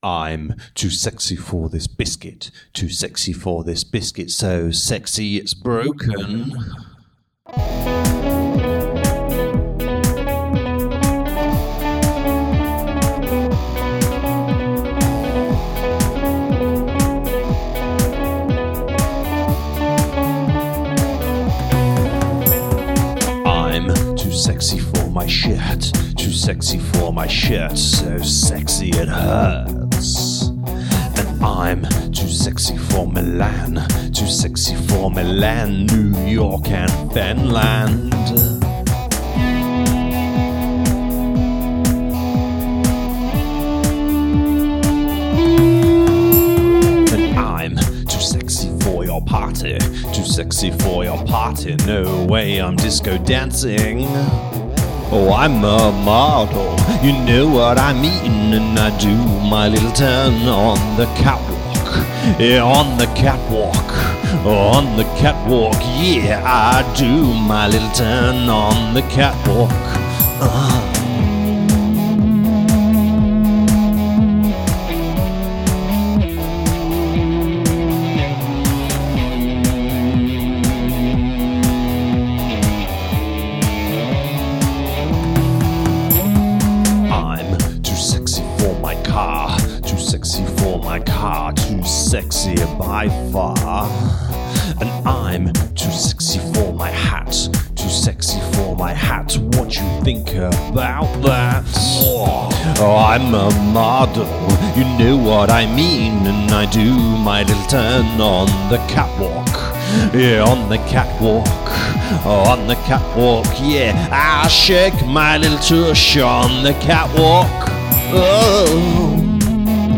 Meow's provided by poor pussy